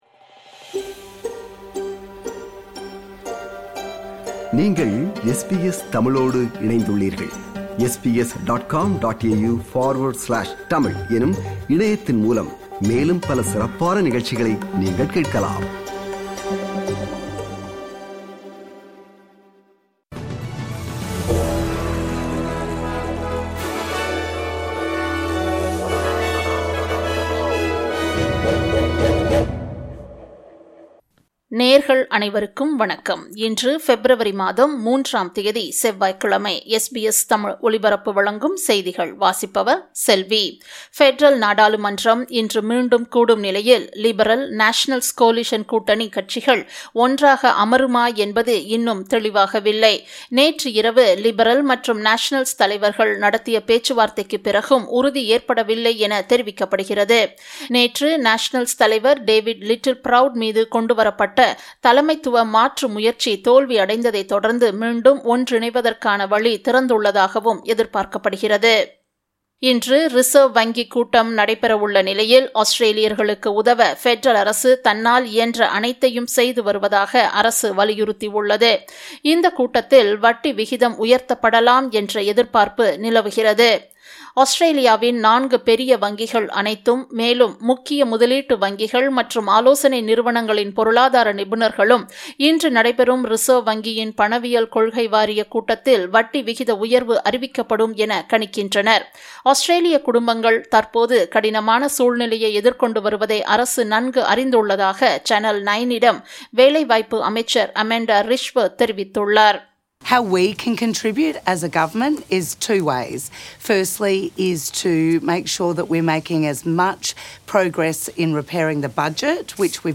இன்றைய செய்திகள்: 03 பெப்ரவரி 2026 - செவ்வாய்க்கிழமை
SBS தமிழ் ஒலிபரப்பின் இன்றைய (செவ்வாய்க்கிழமை 03/02/2026) செய்திகள்.